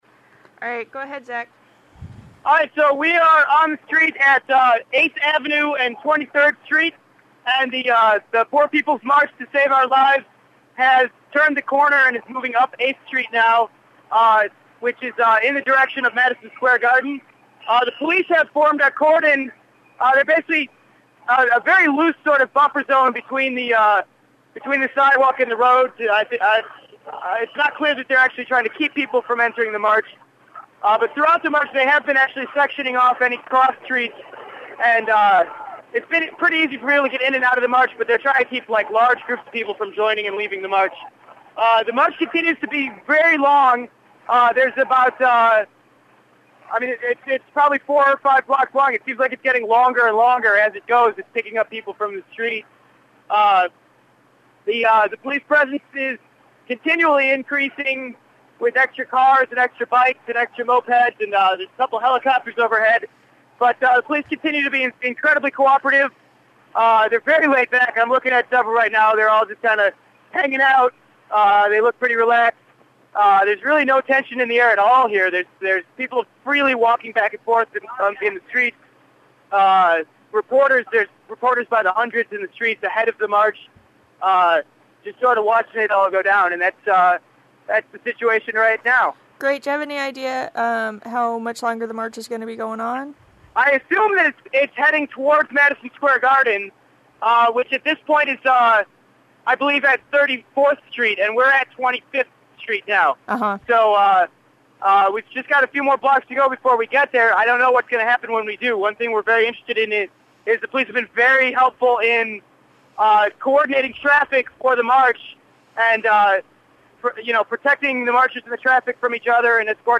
Report from March for Our Lives